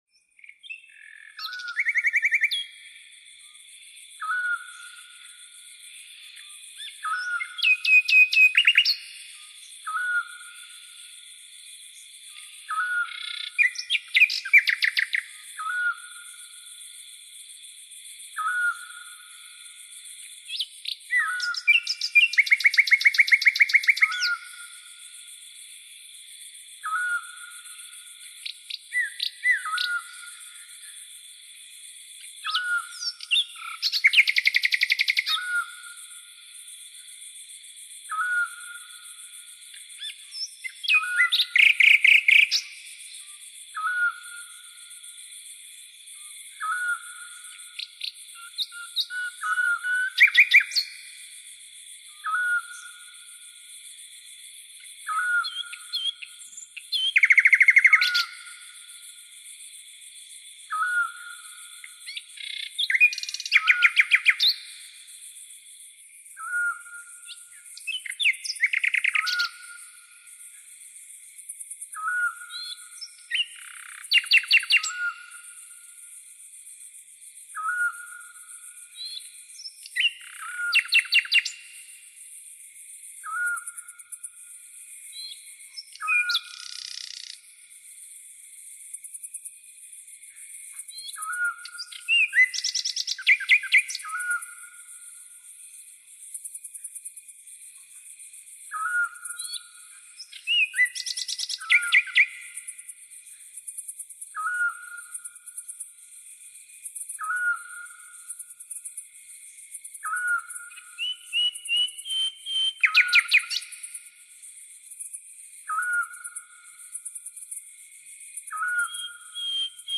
3D spatial surround sound "Birds and crickets"
3D Spatial Sounds